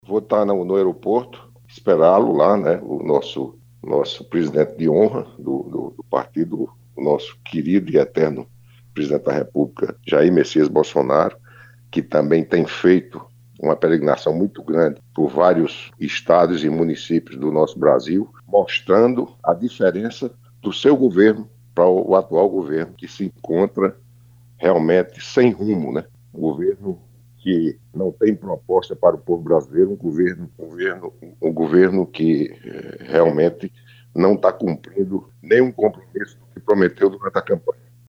Os comentários de Roberto foram registrados pelo programa Correio Debate, da 98 FM, de João Pessoa, nesta terça-feira (09/04).